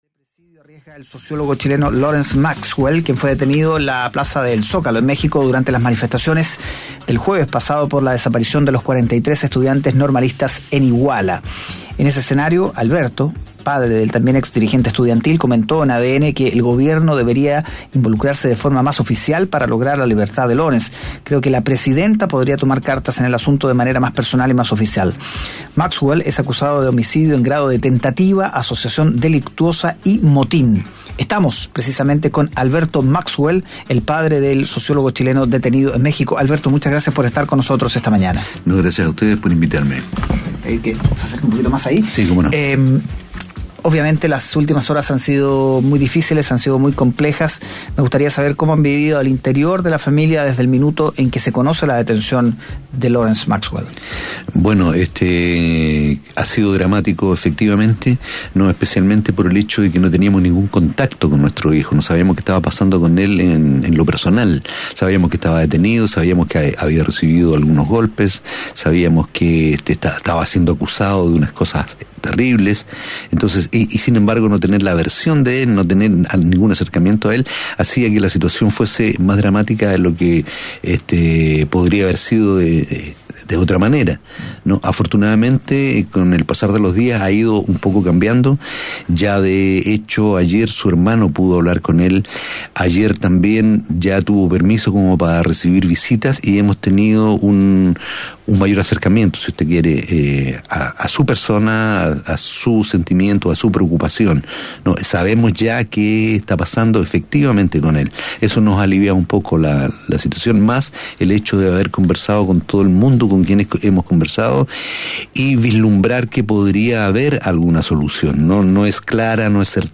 Escucha la entrevista realizada en Mañana Será otro Día: